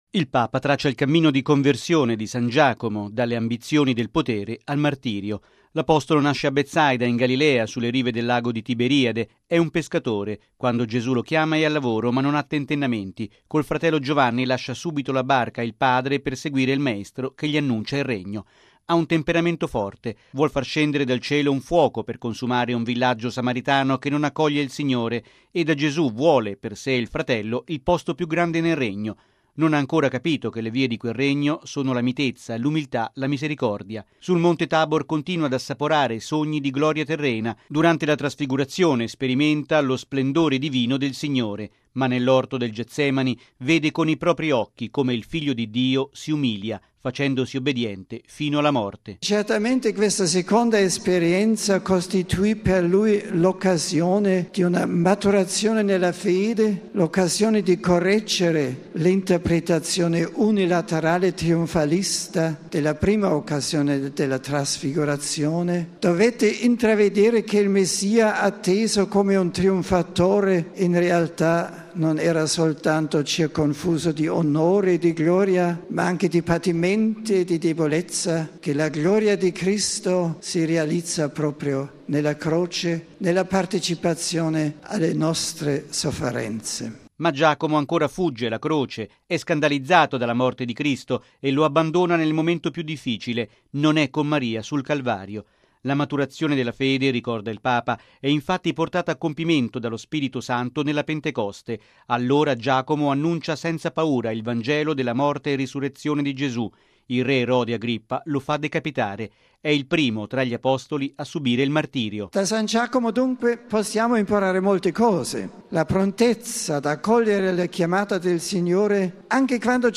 Il Papa ne ha parlato nell’udienza generale del 21 giugno 2006.